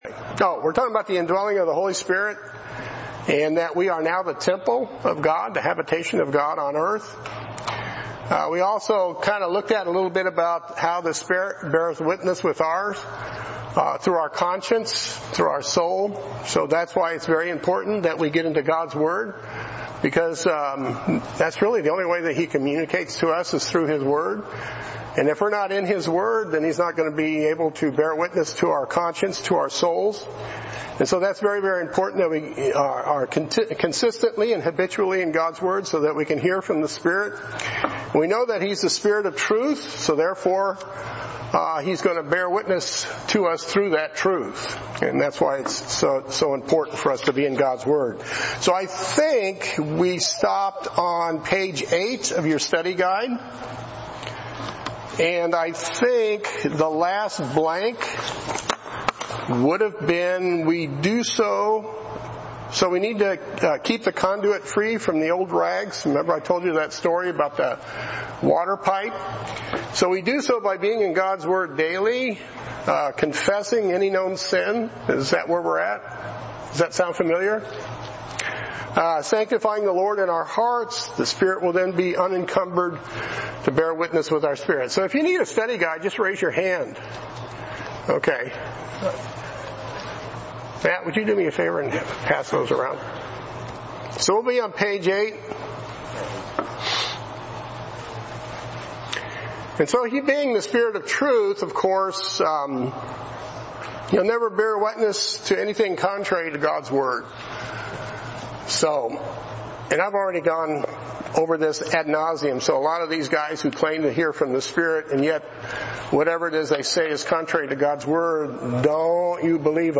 A Study in 1 John Current Sermon